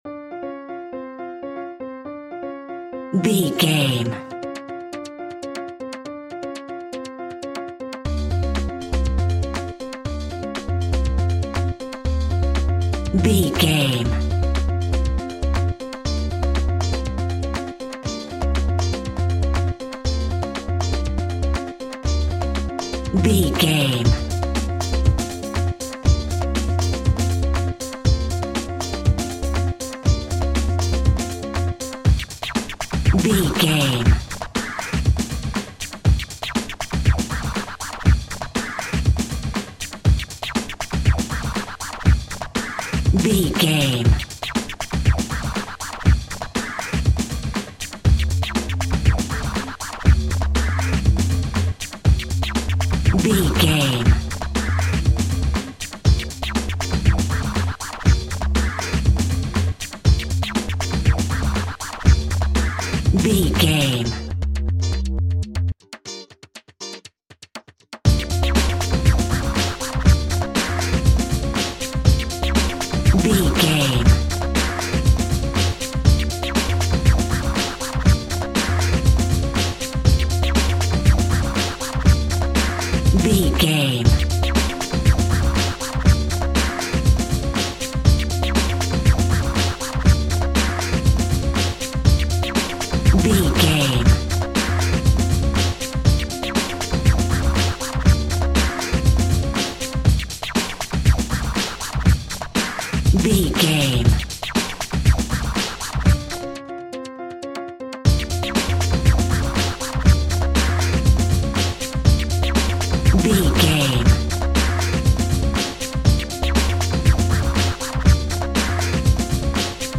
Aeolian/Minor
World Music
ethnic percussion